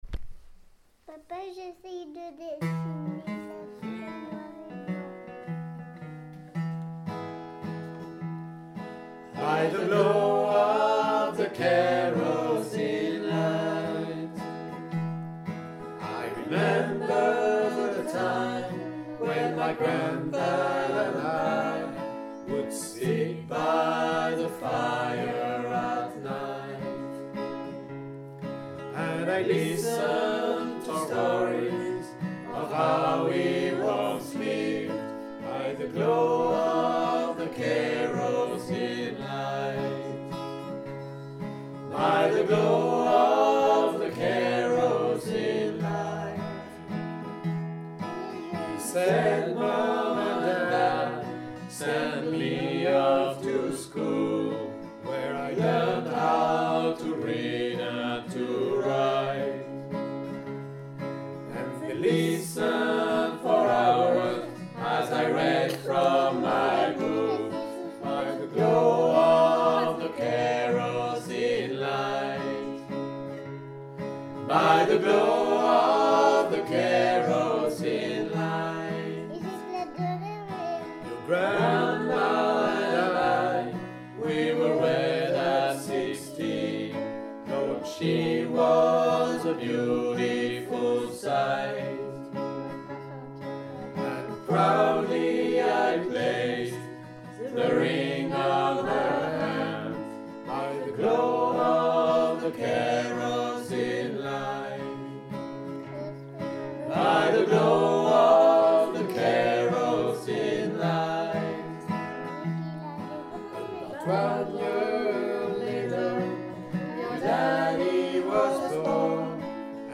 // Mazurka
à la maison (voix et guitare